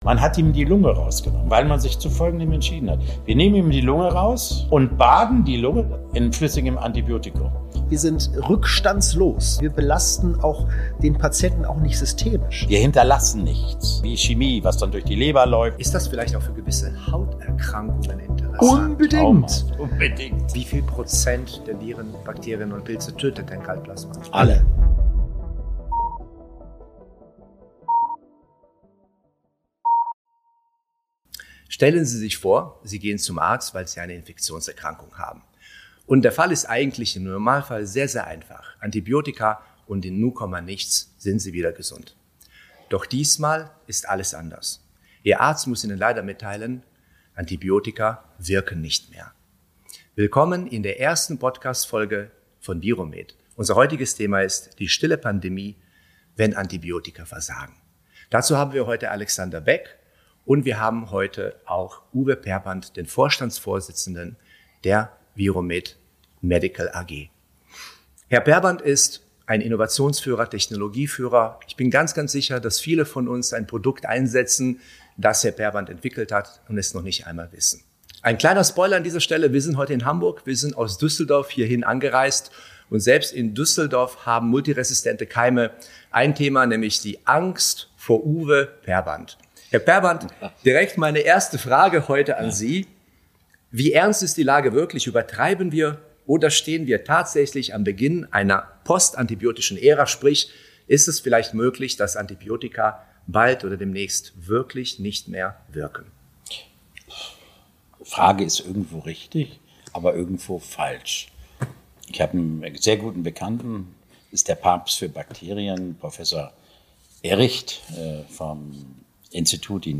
Ein Gespräch über Medizin, Innovation und die Frage, wie die Zukunft der Infektionsbehandlung aussehen könnte.